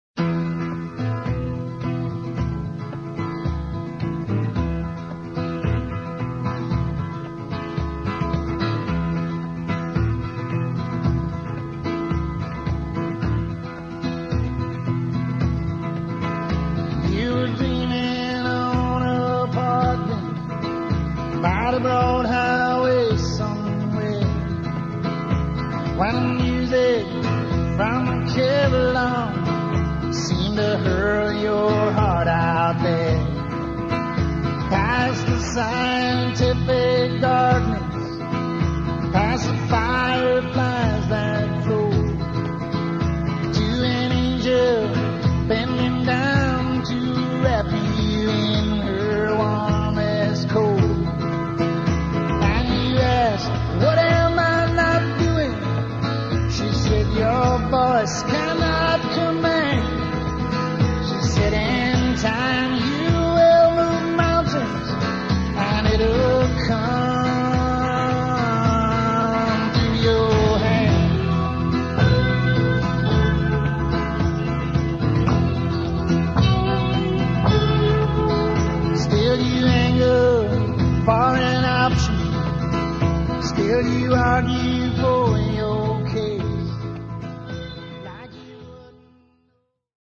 please note: demo recording
location: Nashville, date: unknown